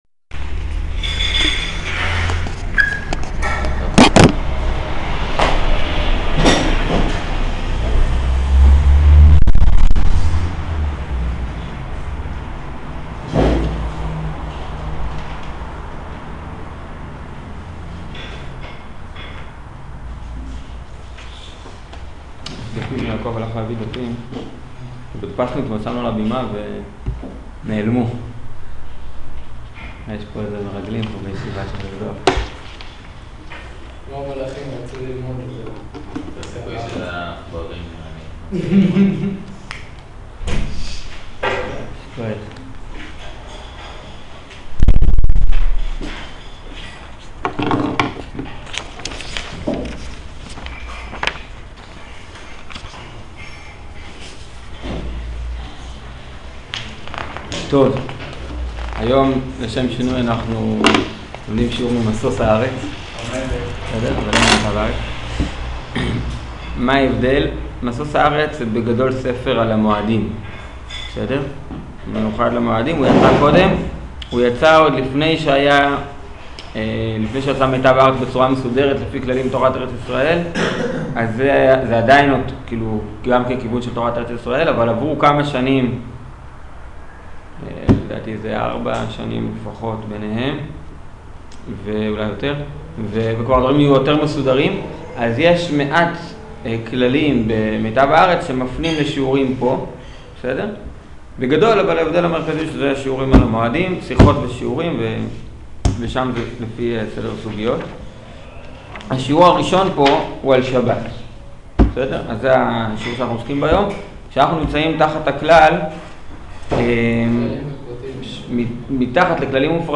שיעור גבול כממוצע